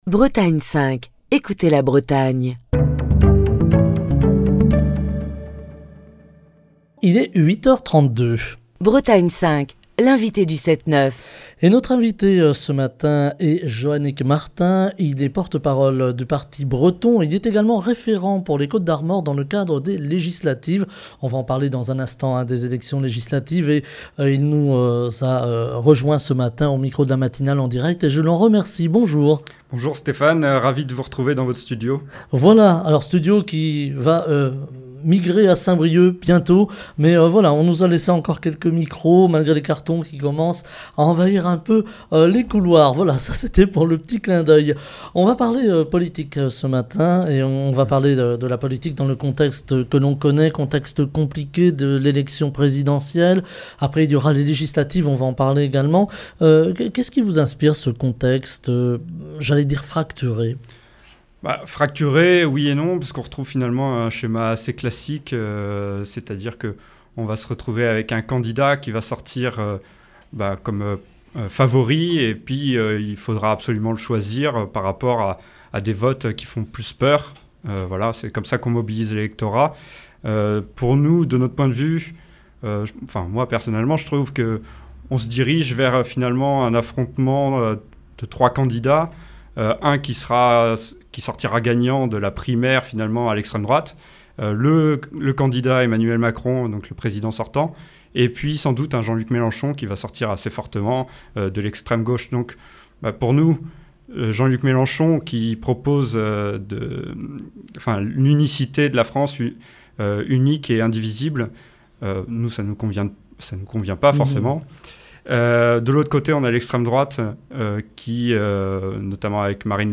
Bretagne 5 Matin. Écouter Télécharger Partager le podcast Facebook Twitter Linkedin Mail L'invité de Bretagne 5 Matin